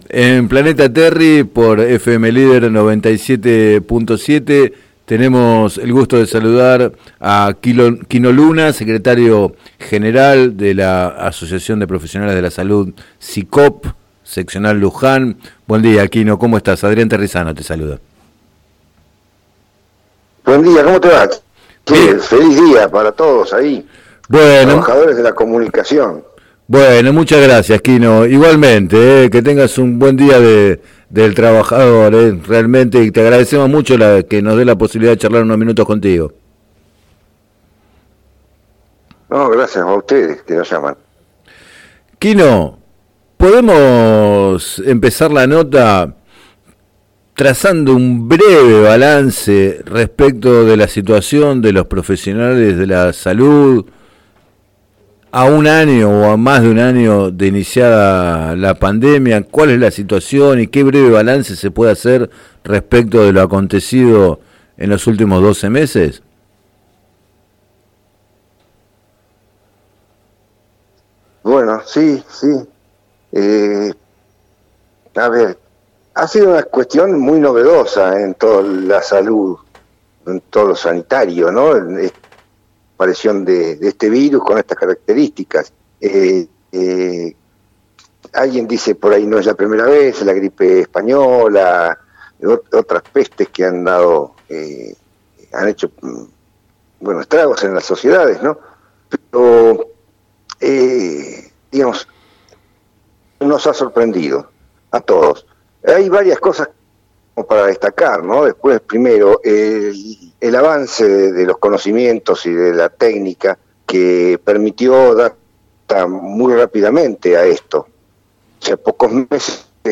Consultado en el programa Planeta Terri acerca del proyecto del concejal